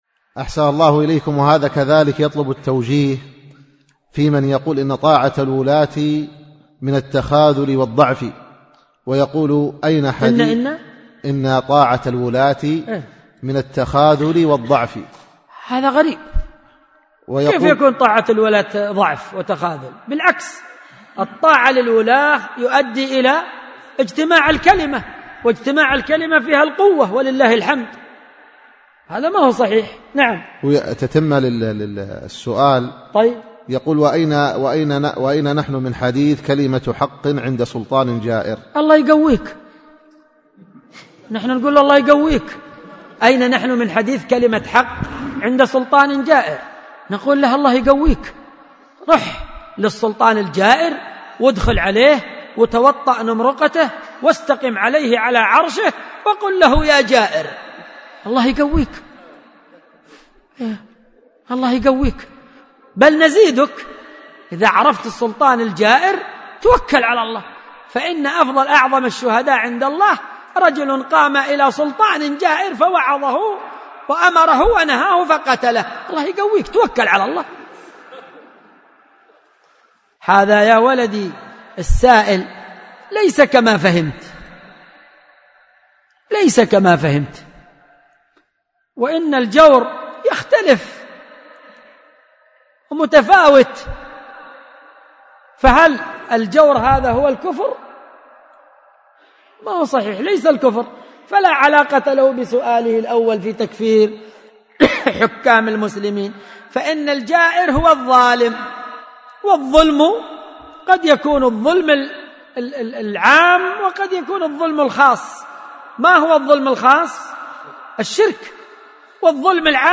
ملف الفتوي الصوتي عدد الملفات المرفوعه : 1